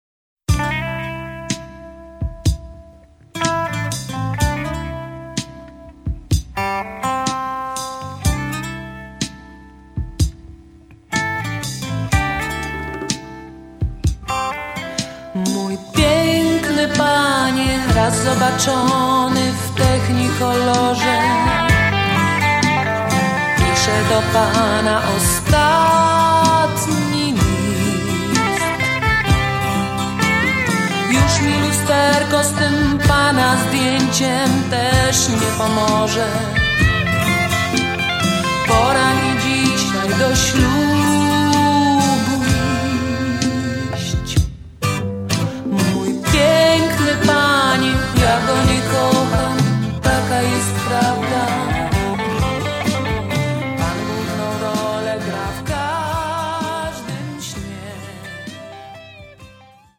VOC GUITAR KEYB BASS DRUMS TEKST
polski zespół wykonujący muzykę pop, folk rock i folk